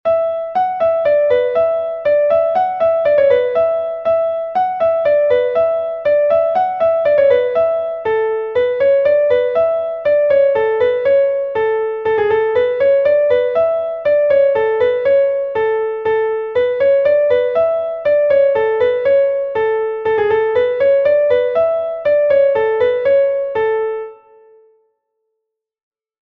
Ridée du Pays de Redon I est un Laridé de Bretagne enregistré 1 fois par Pevar Den